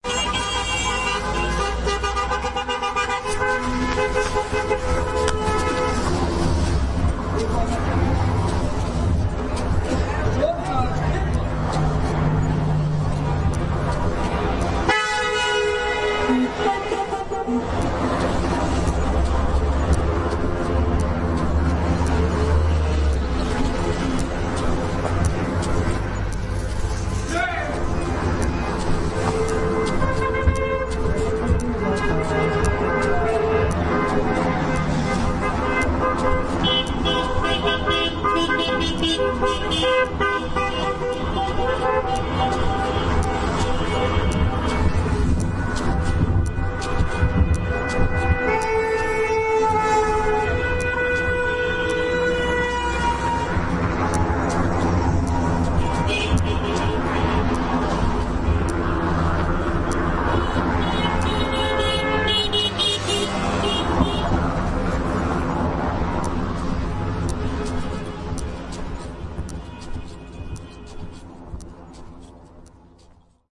Звук автомобильного клаксона, который сигналит на перекрестке